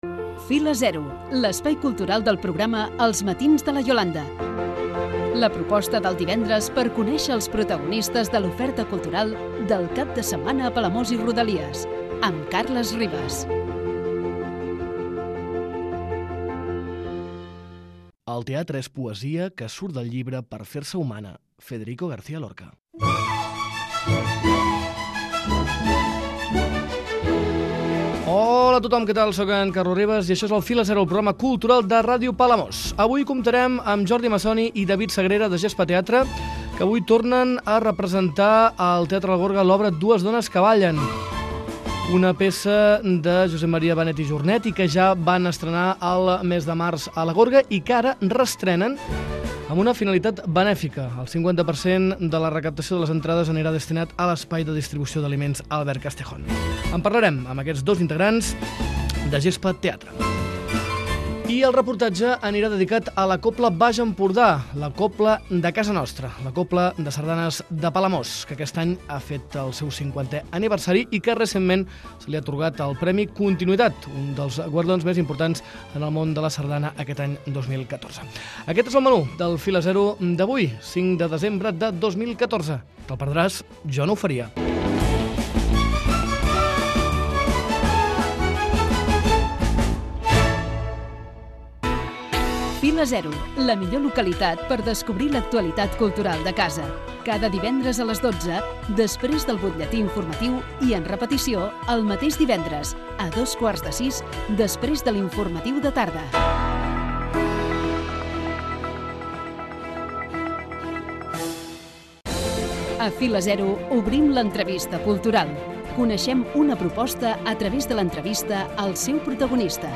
Reportatge dedicat a la Cobla Baix Empordà, distingida recentment amb un dels premis Capital de la Sardana 2014.